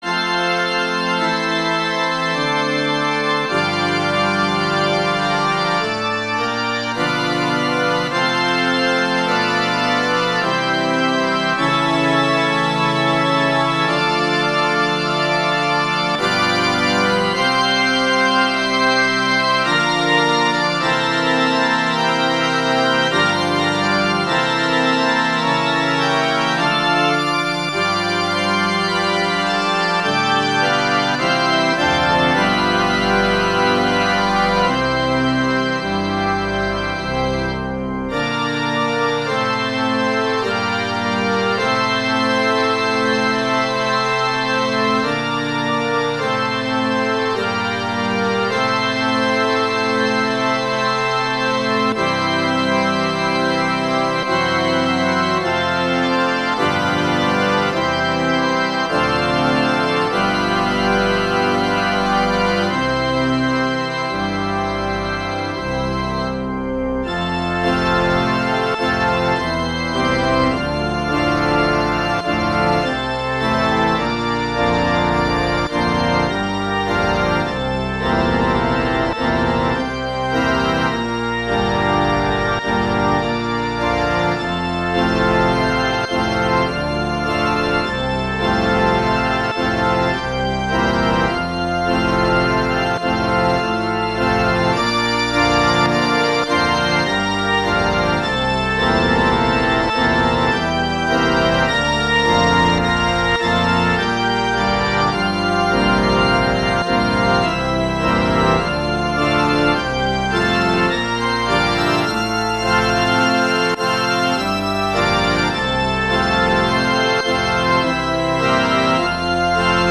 Instrumentation: organ solo
classical, wedding, festival, love
F major
♩=52 BPM